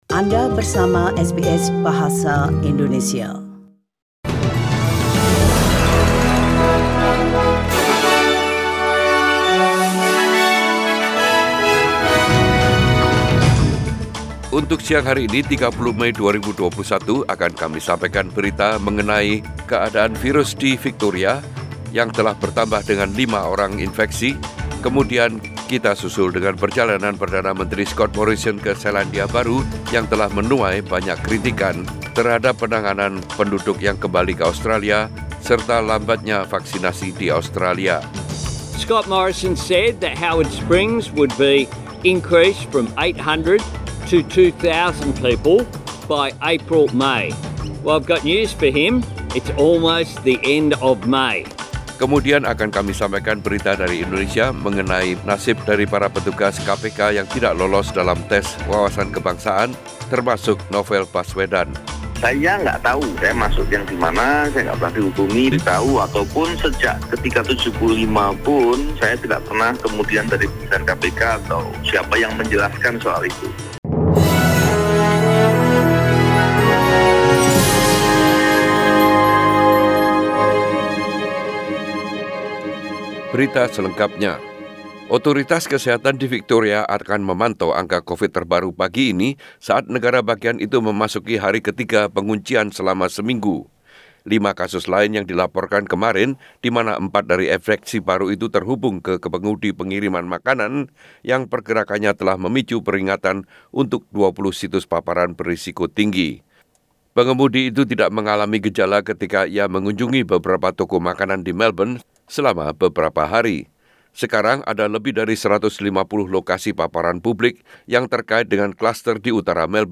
SBS Radio news in Bahasa Indonesia - 30 May 2021
Warta Berita Radio SBS Program Bahasa Indonesia.